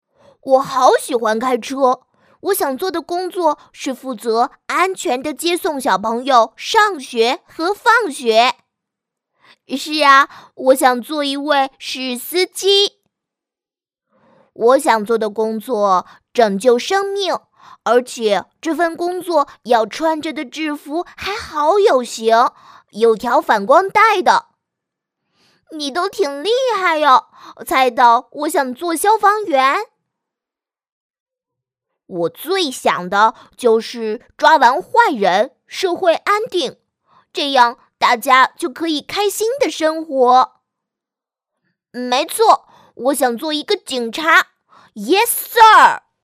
当前位置：首页 > 配音题材 > 童声配音
童声配音即儿童音或者少年音，也叫小孩音。